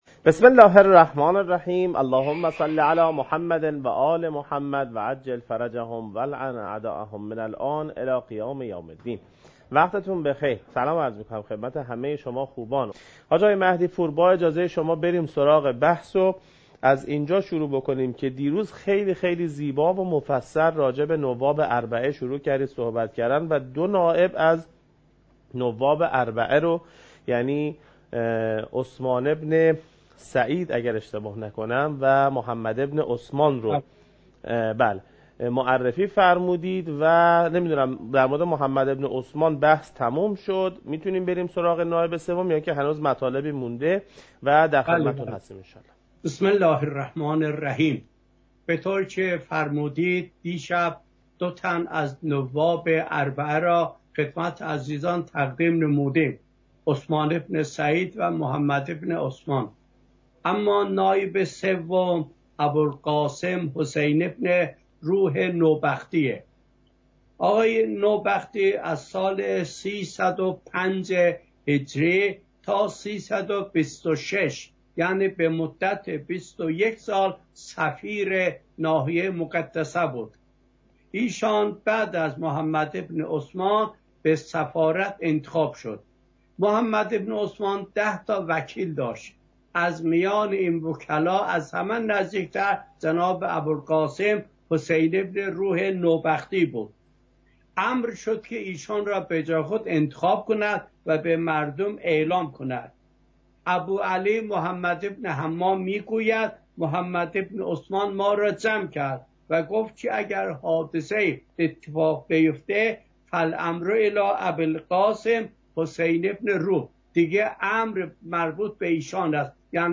حجم: 10.2 MB | زمان: 43:35 | تاریخ: 1441هـ.ق | مکان: کربلا